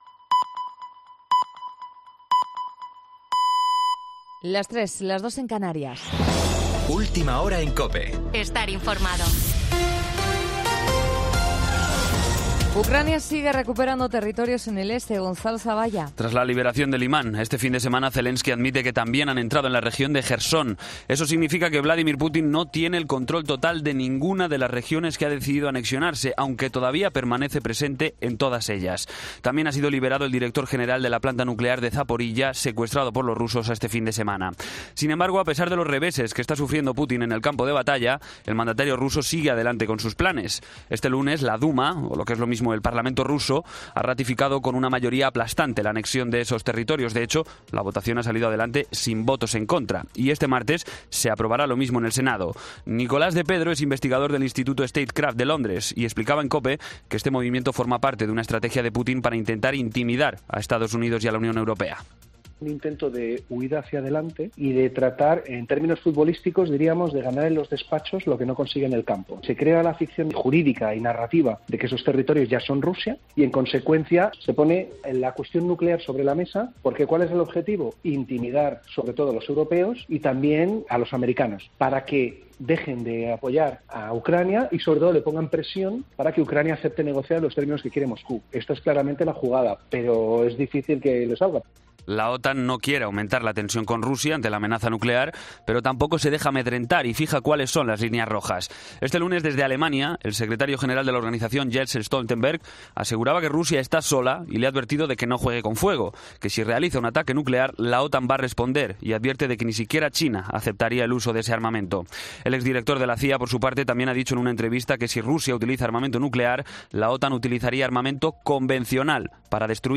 Boletín de noticias COPE del 04 de octubre a las 03:00 hora
AUDIO: Actualización de noticias de Herrera en COPE